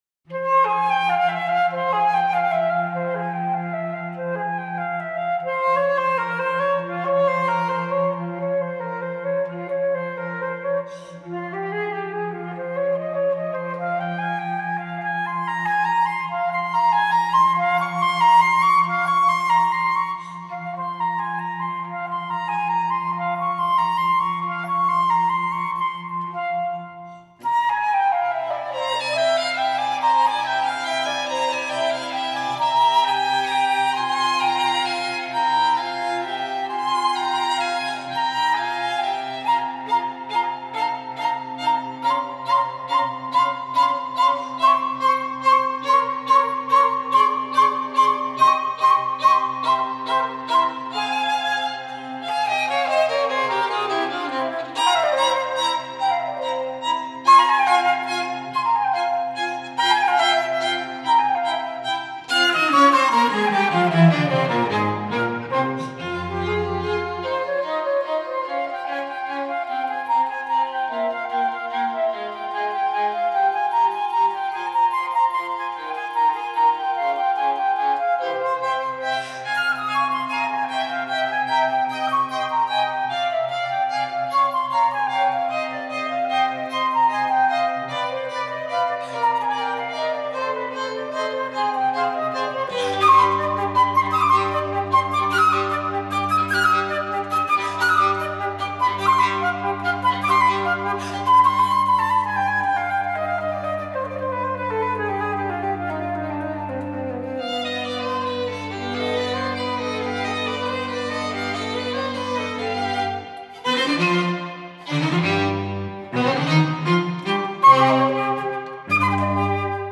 گل انفرادی و تماشایی استوائو به بارسلونا / فیلم برچسب‌ها: antonio vivaldi Four seasons winter Allegro violin دیدگاه‌ها (5 دیدگاه) برای ارسال دیدگاه وارد شوید.
silverwood_quartet_-_vivaldi_winter_from_the_four_seasons_3.mp3